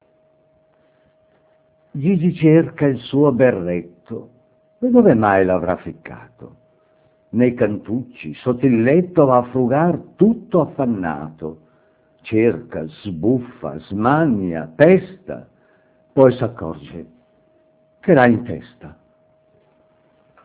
Recitato